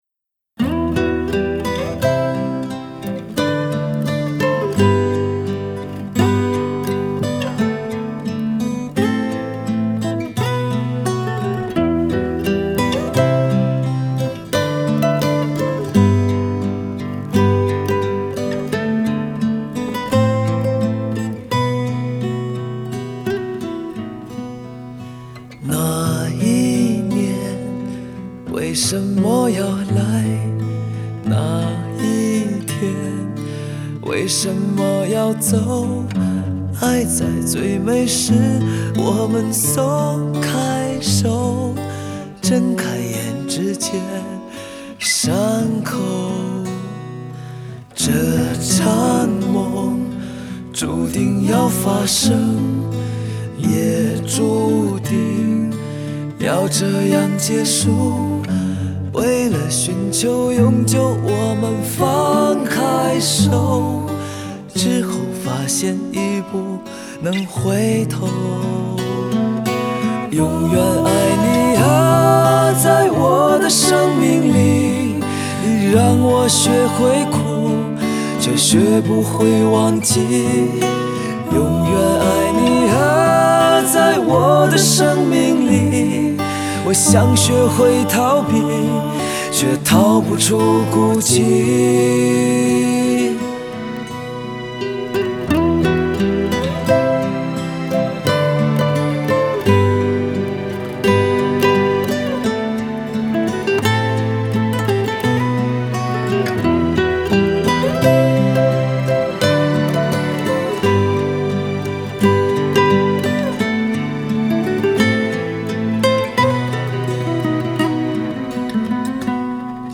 表示这张专辑将古典和流行完美结合， 又溶入了摇滚的元素